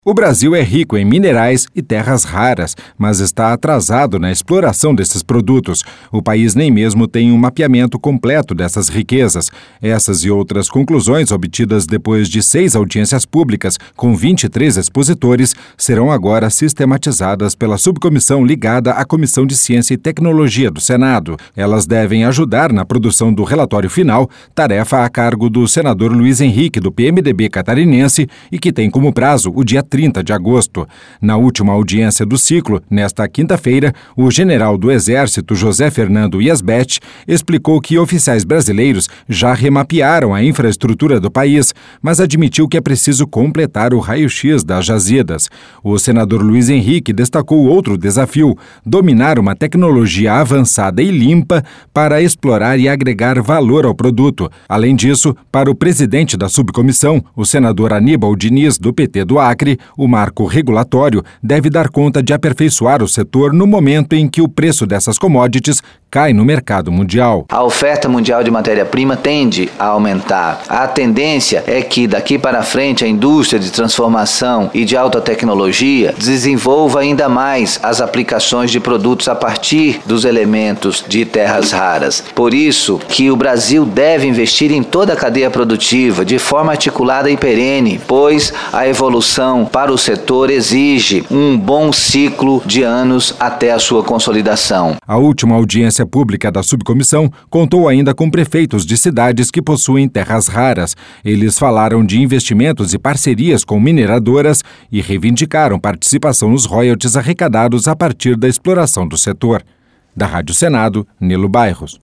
Além disso, para o presidente da subcomissão, o senador Aníbal Diniz, do PT do Acre, o marco regulatório deve dar conta de aperfeiçoar o setor no momento em que o preço dessas commodities cai no mercado mundial: